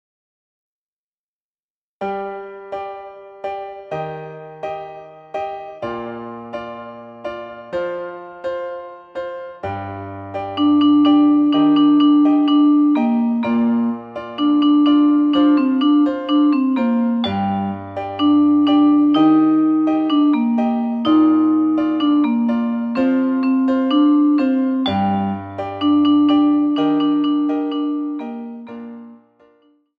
Recueil pour Cor